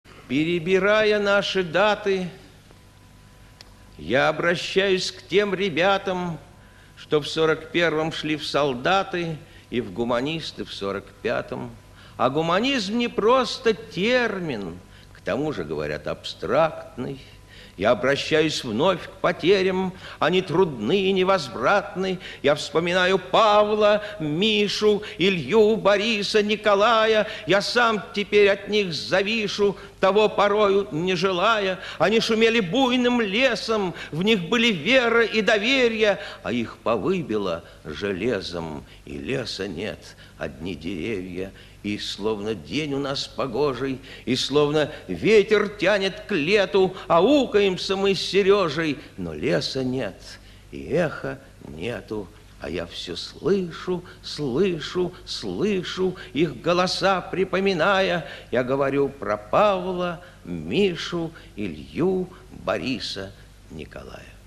3. «Давид Самойлов – Перебирая наши даты (читает автор)» /